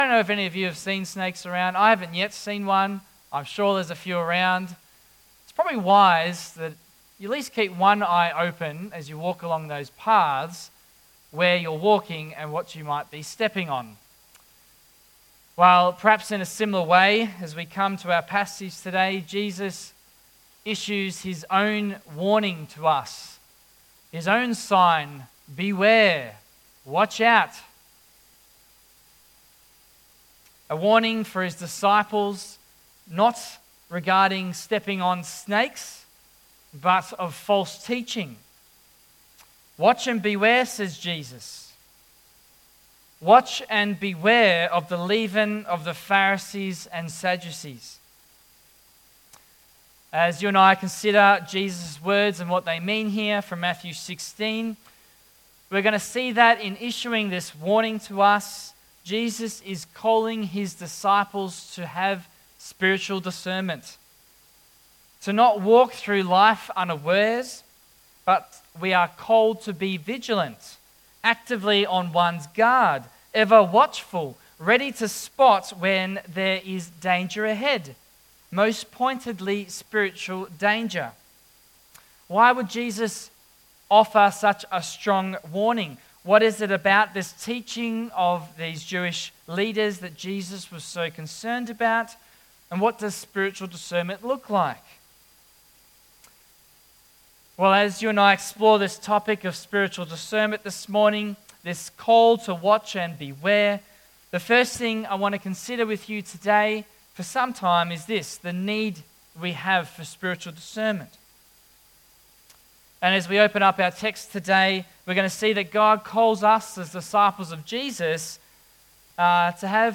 Christian Reformed Church of Geelong, Sunday 9 th February 2025
Passage: Matthew 16:1-12 Service Type: AM